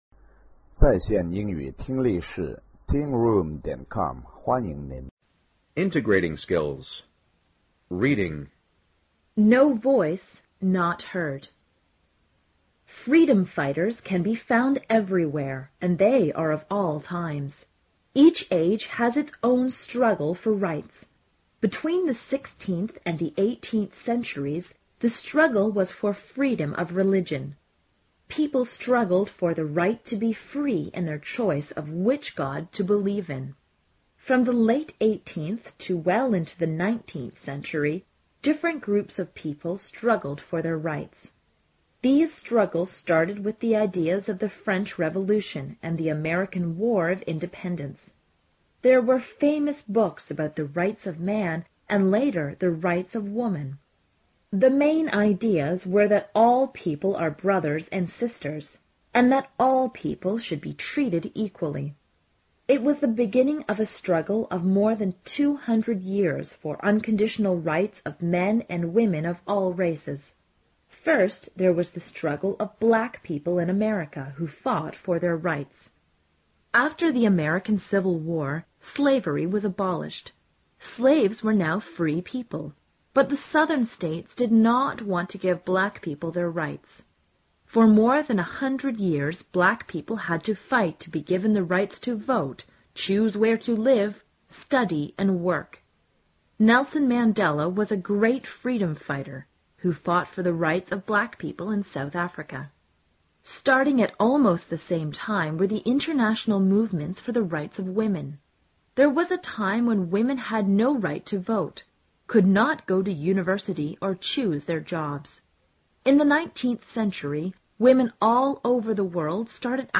高中英语第二册课本朗读14-a 听力文件下载—在线英语听力室